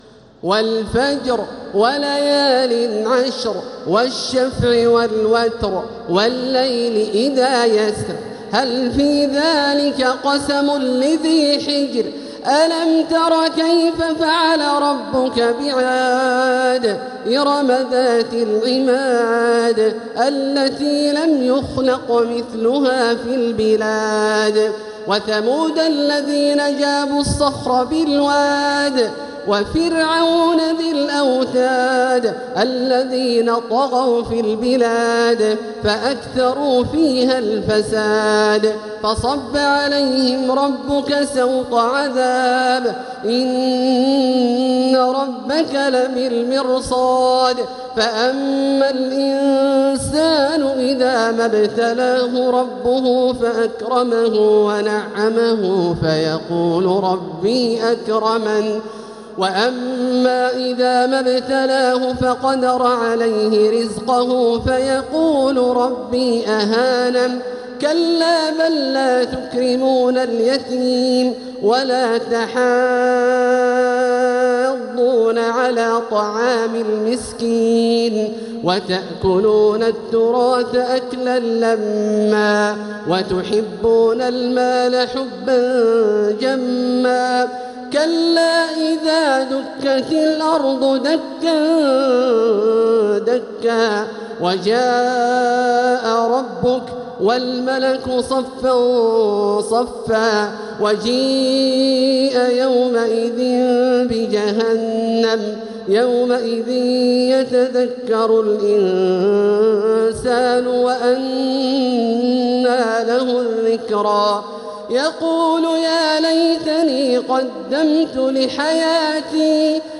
سورة الفجر | مصحف تراويح الحرم المكي عام 1446هـ > مصحف تراويح الحرم المكي عام 1446هـ > المصحف - تلاوات الحرمين